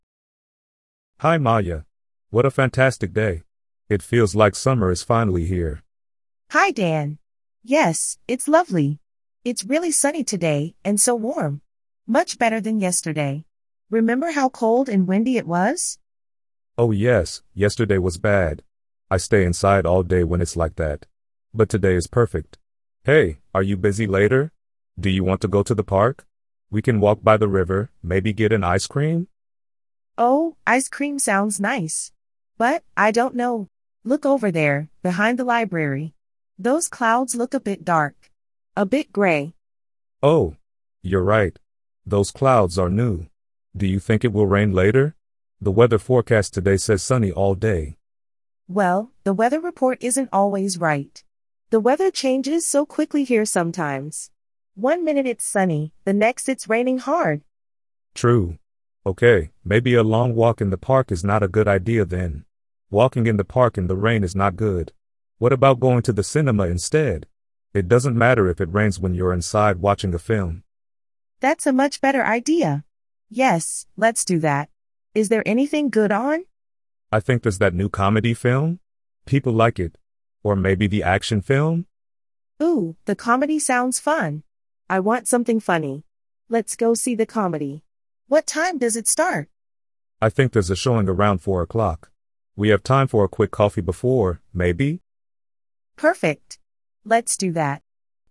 Beginner Listening Practice